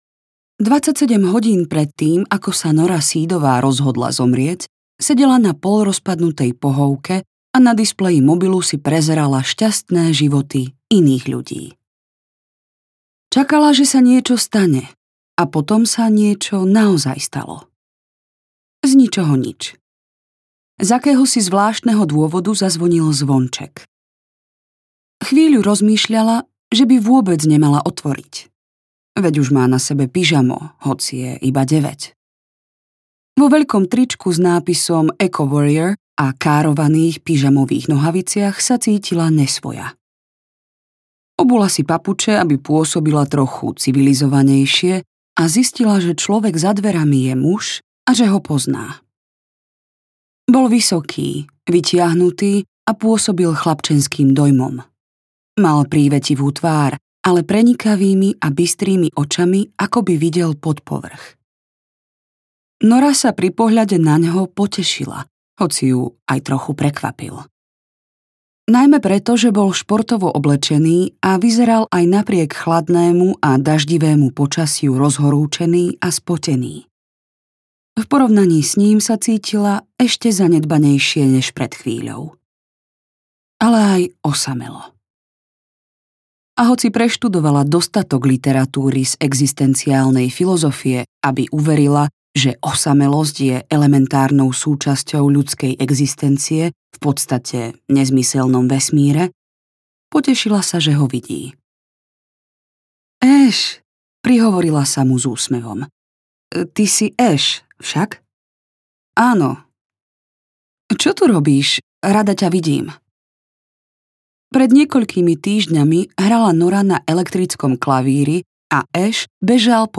Polnočná knižnica audiokniha
Ukázka z knihy
polnocna-kniznica-audiokniha